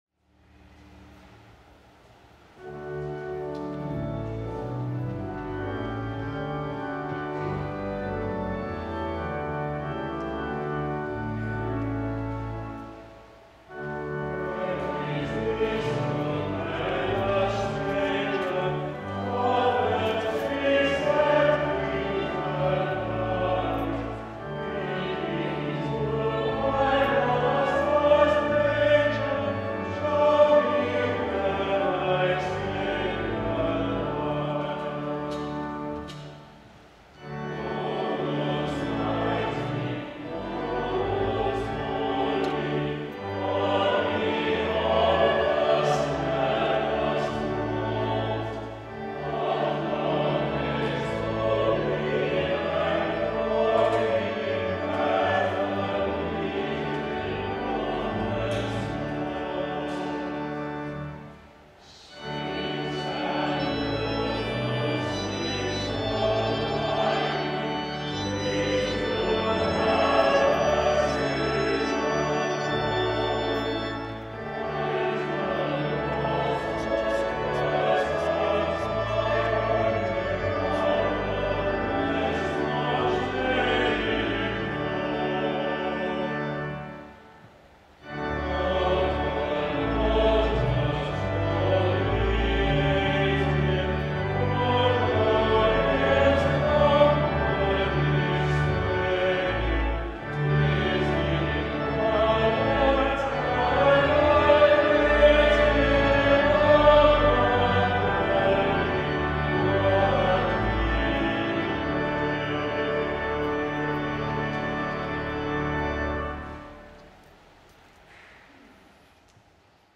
[Unfortunately, this is the only version of the hymn I could find set to the tune Haron Holgate and the words sung here differ from what appear in our hymn book – given above.]